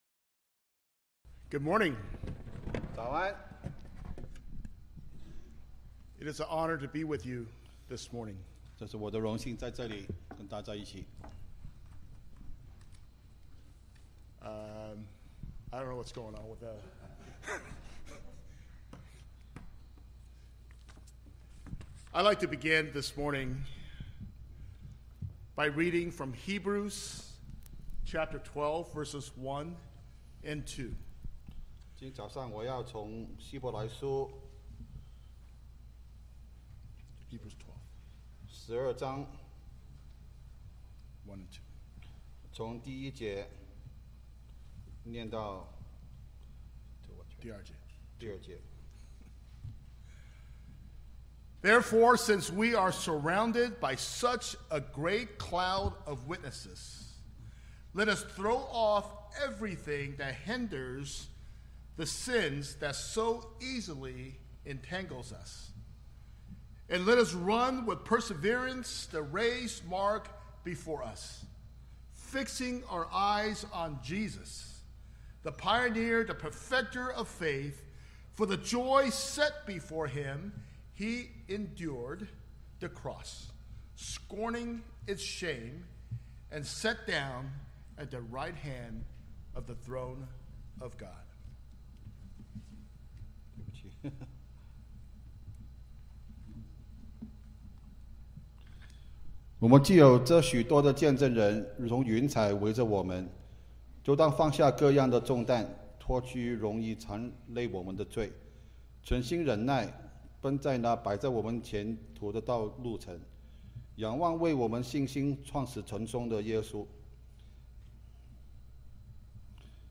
約翰福音 Passage: 約翰福音 6 章1-14 節 Service Type: 三堂聯合崇拜 - 英語 1 這事以後、耶穌渡過加利利海、就是提比哩亞海。